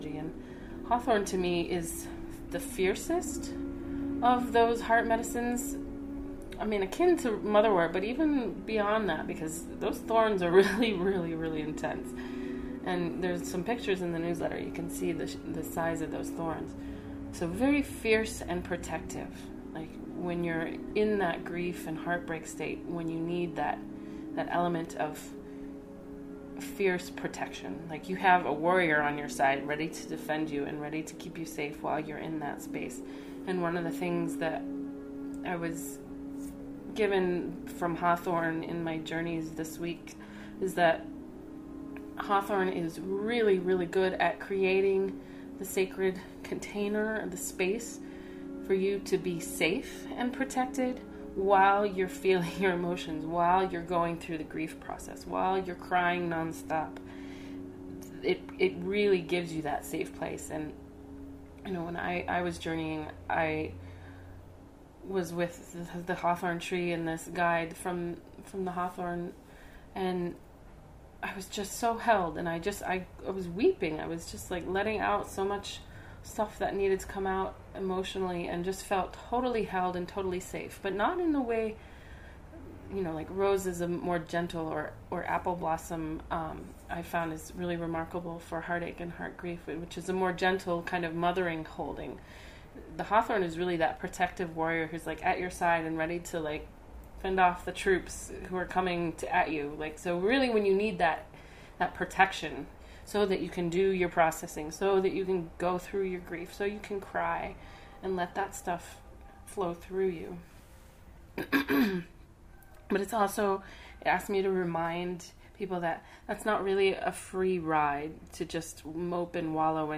Sacred Plant Journey, Guided Meditation, Journal Questions for deepening your relationship and work with Hawthorn as an ally for emotional and spiritual transformation, music, poetry and more!